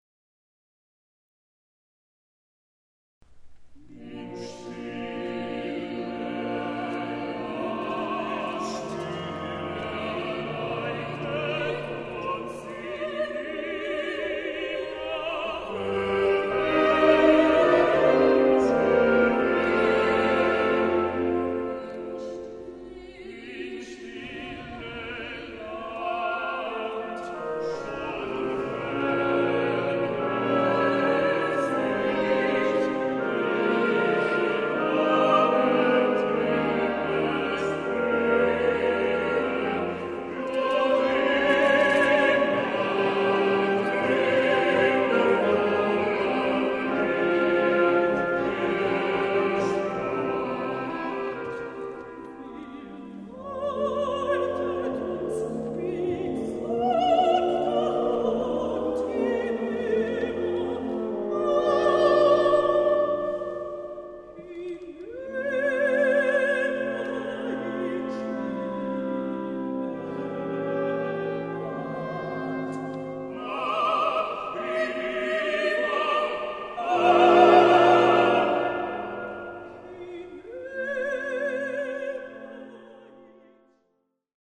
02 Quartett "Ins Stille Land"